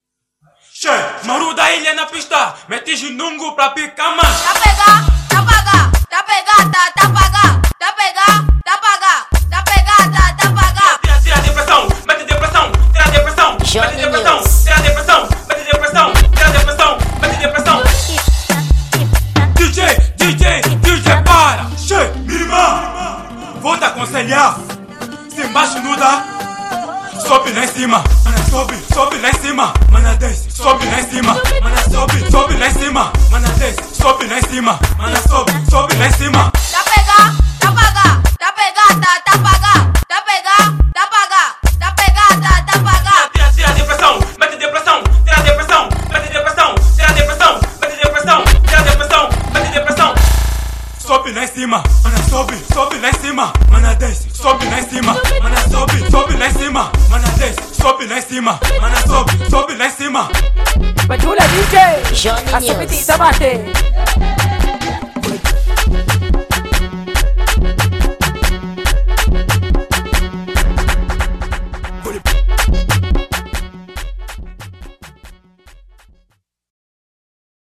kuduro animado feito para levantar qualquer pista.
Gênero: Kuduro